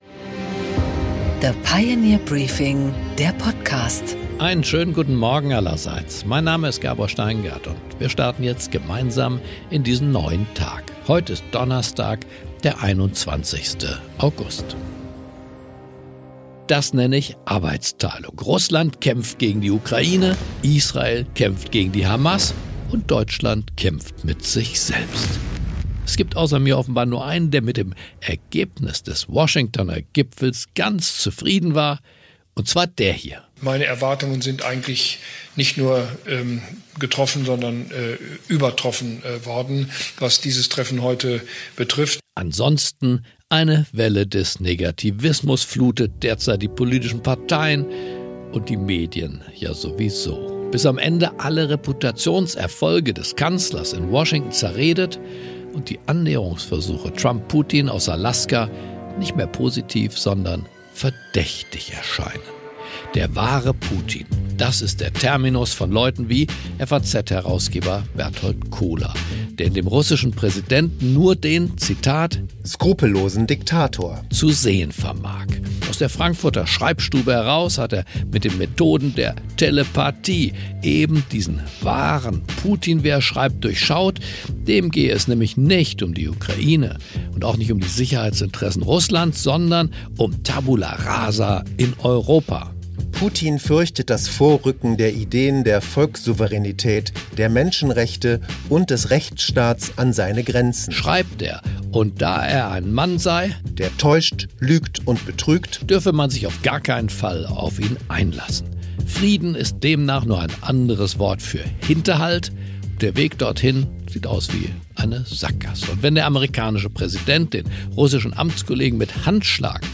Gabor Steingart präsentiert das Pioneer Briefing.
Im Gespräch: Rüdiger von Fritsch, Ex-Botschafter in Moskau, spricht mit Gabor Steingart über Putins Kriegslogik, gescheiterte Diplomatie und die Möglichkeiten Europas in dem Ukraine-Konflikt.